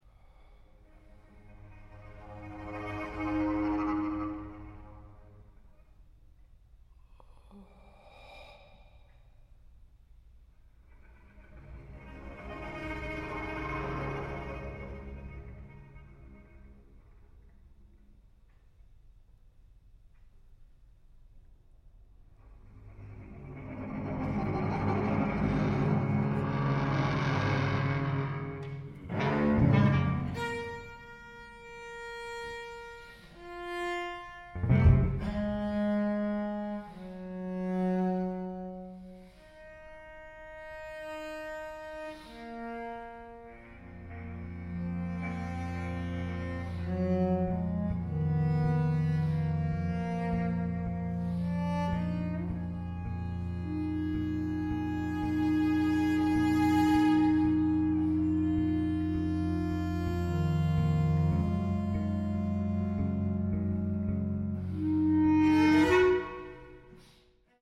• Genres: Classical, Vocal